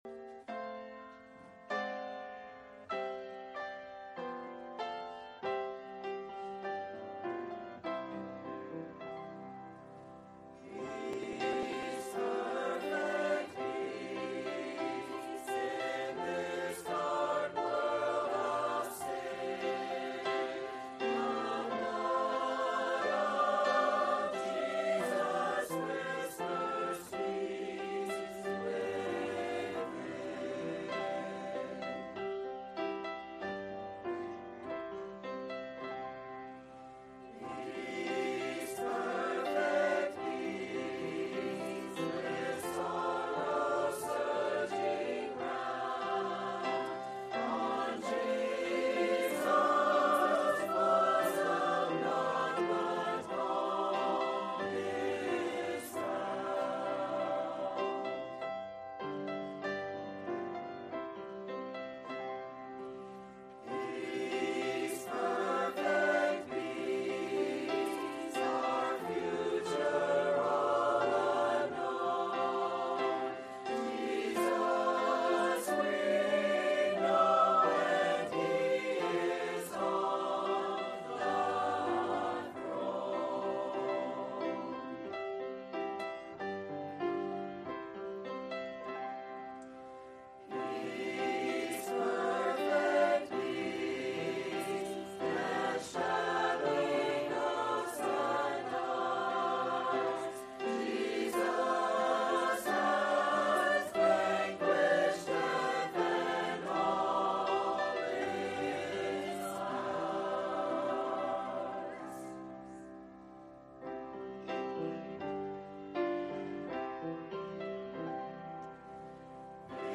Standalone Sunday Messages at PBC